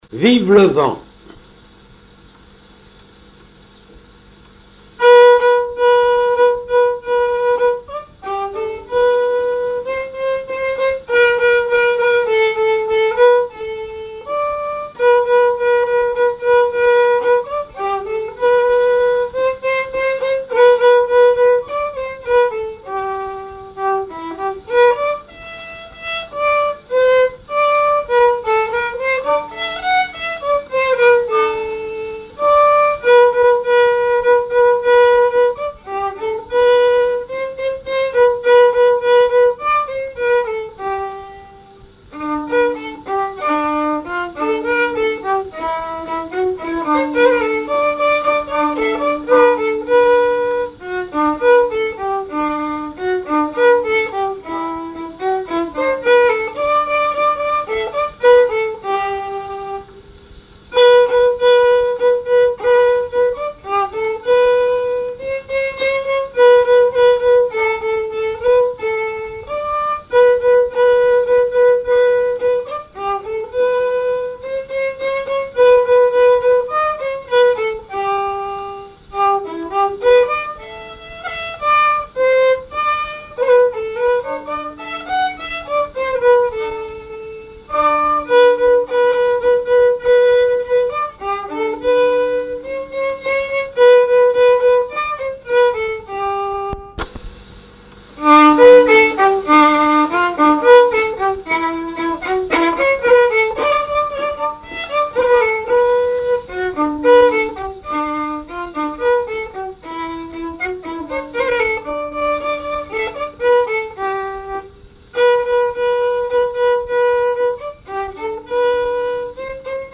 Noël, Nativité
Genre strophique
Pièce musicale inédite